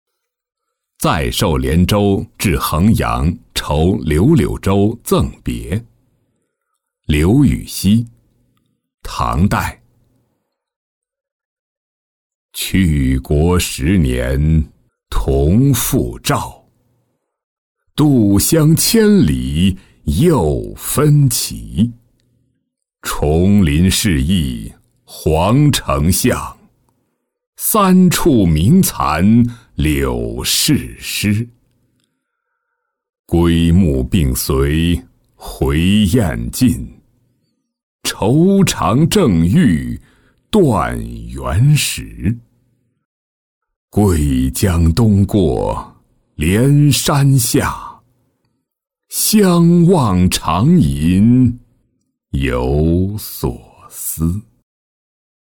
再授连州至衡阳酬柳柳州赠别-音频朗读